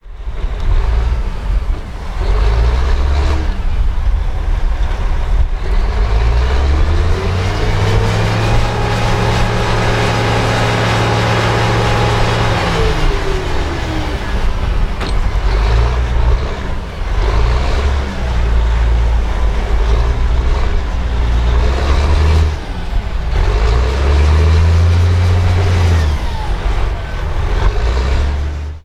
Ambiente de una obra de edificación de viviendas